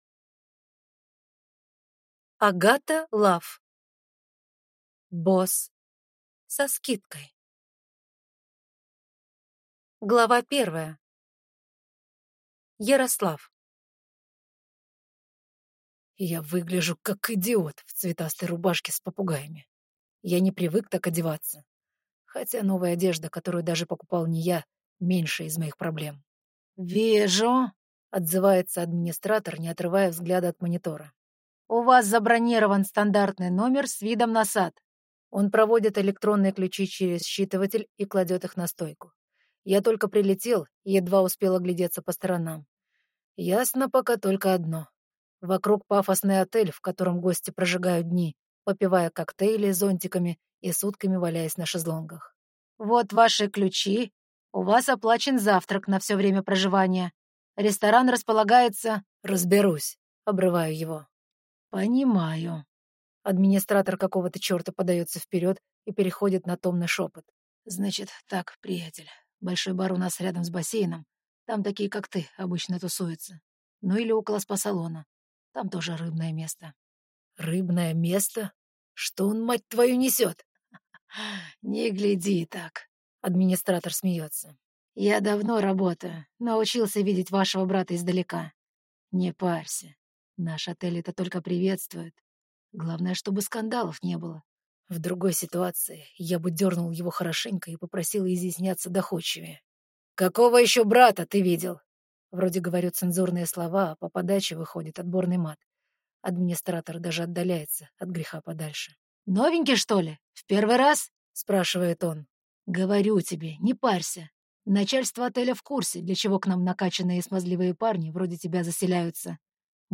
Аудиокнига Босс со скидкой | Библиотека аудиокниг